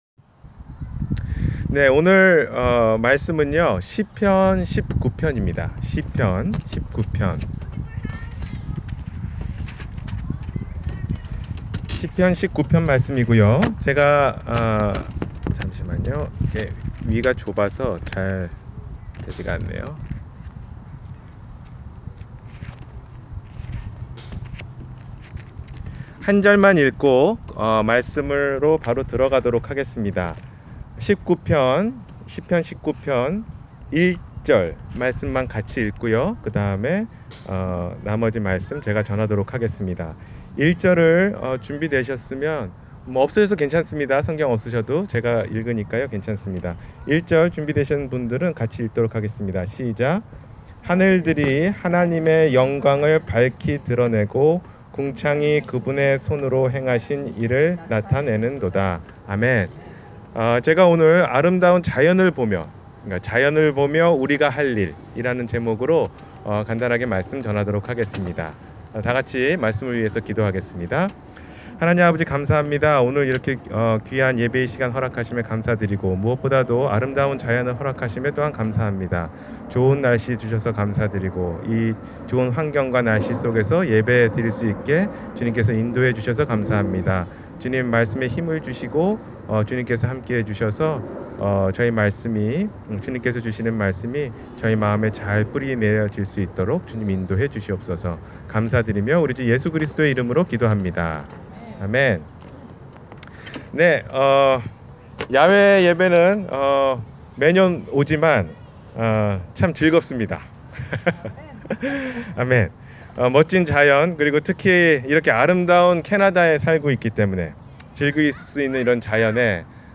자연을 보며 우리가 할 일 – 주일설교